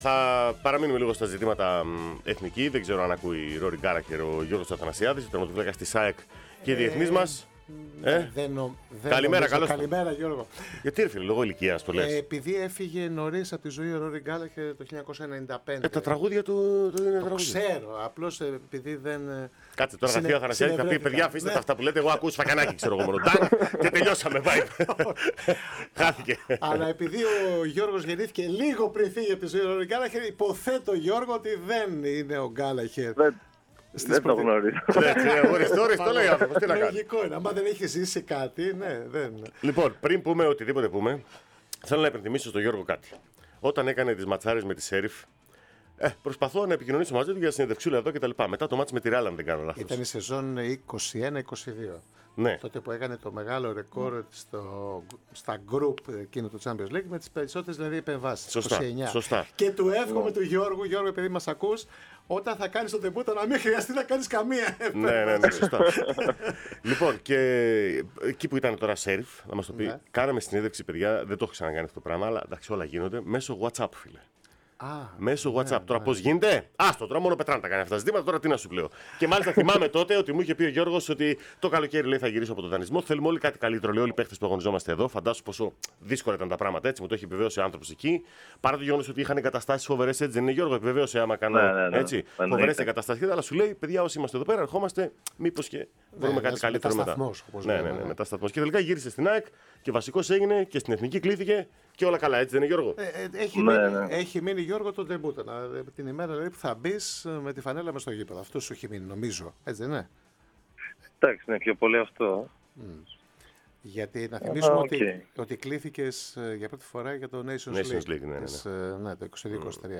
Ο 30χρονος διεθνής τερματοφύλακας φιλοξενήθηκε στην εκπομπή της ΕΡΑΣΠΟΡ “Πρώτη Σελίδα”